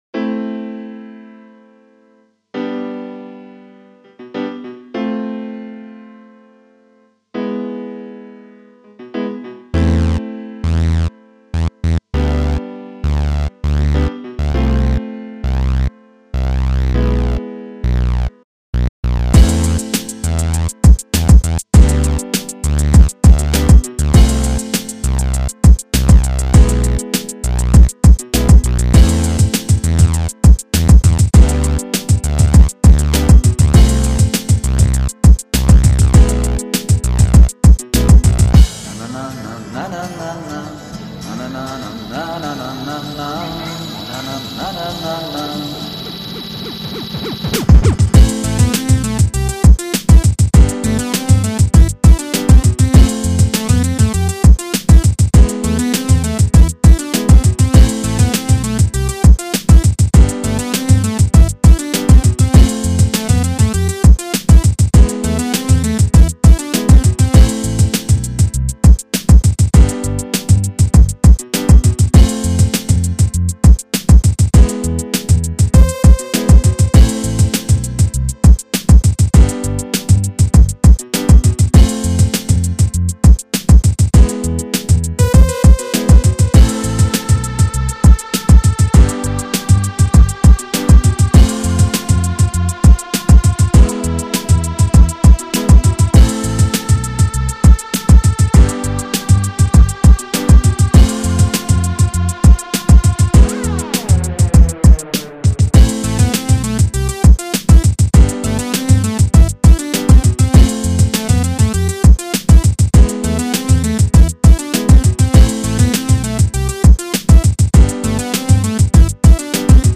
Cover-Mix